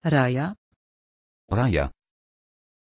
Aussprache von Raja
pronunciation-raja-pl.mp3